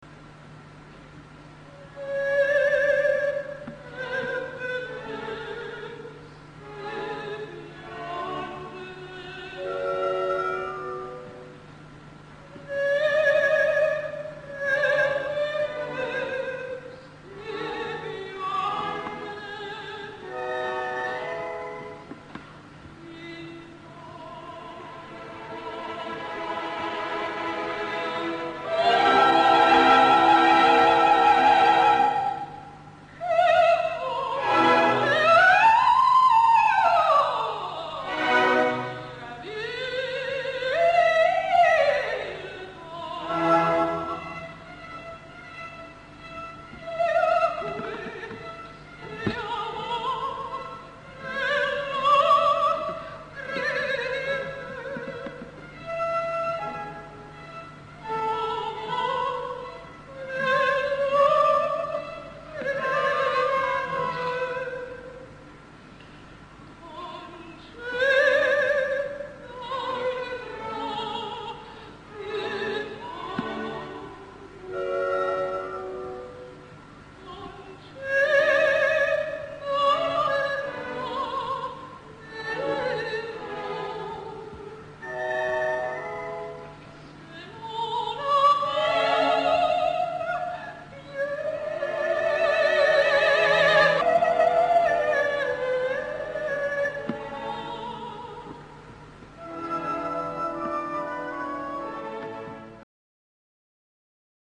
Aria di Ermione (